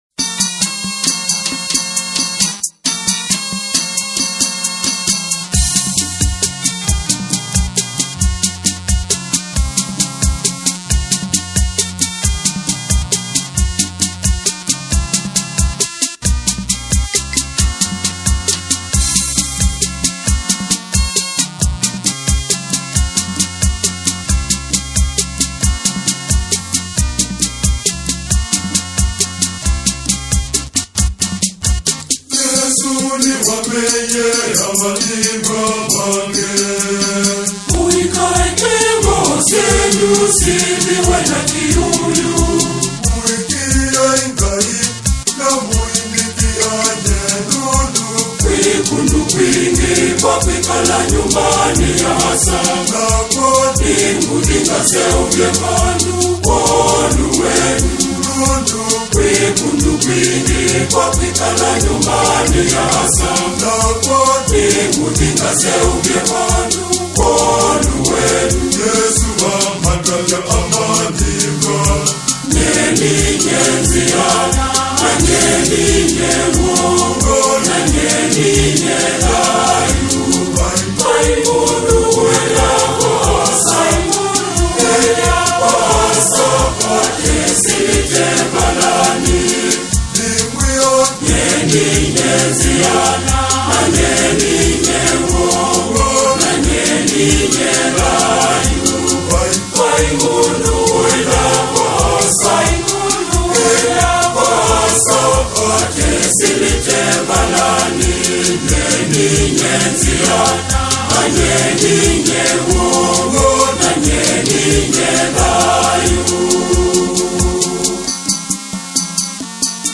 soul-stirring and culturally vibrant single
ensemble